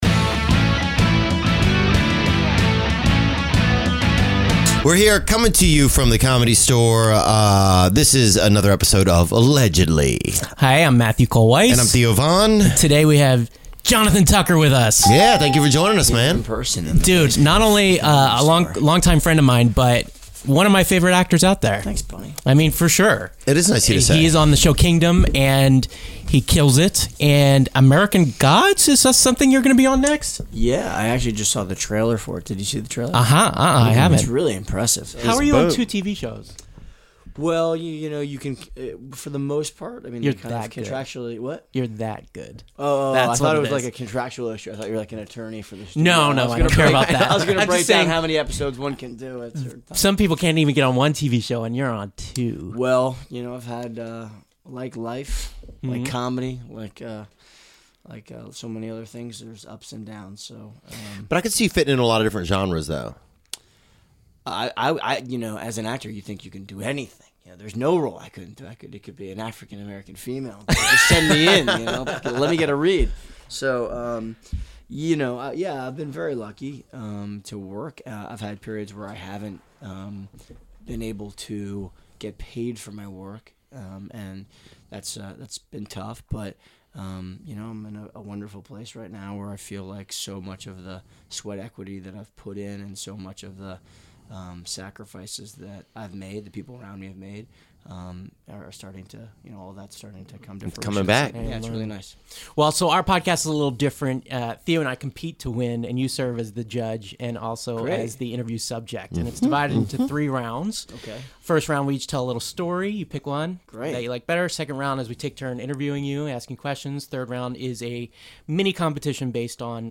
He may play the agro Jay on "Kingdom", but Jonathan Tucker is nothing but a teddy bear in our studio! The veteran actor who has starred on "Justified" and "Parenthood" and classic films like "The Virgin Suicides" and "Sleepers" talks about his career, his marriage, and what Nick Jonas looks like naked.